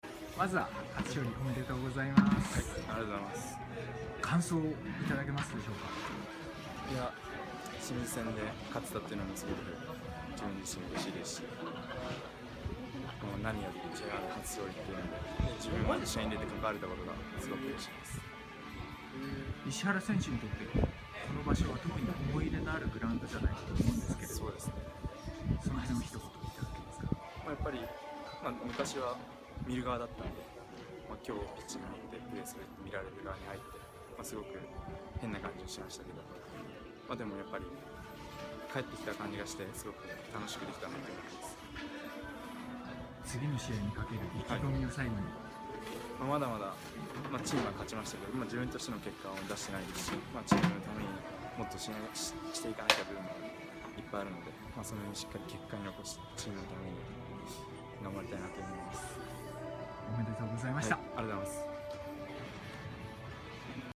インタビュー